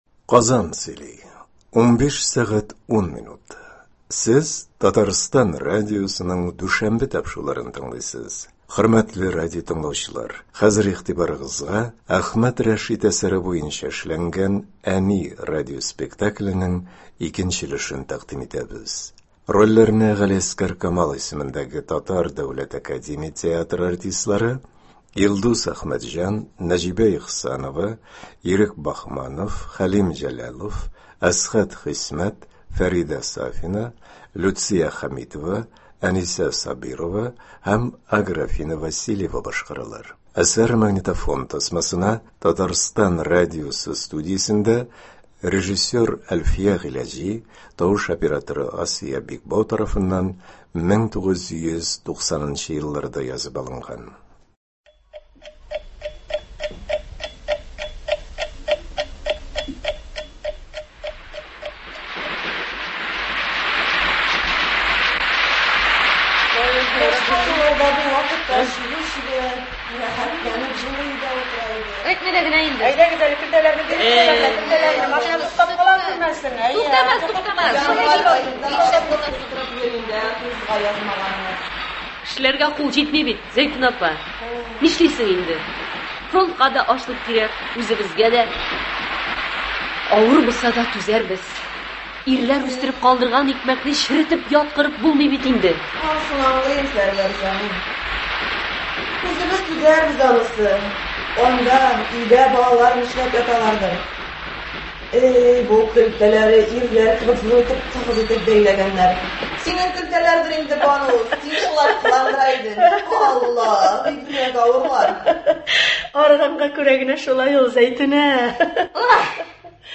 “Әни”. Радиоспектакль. 1 өлеш.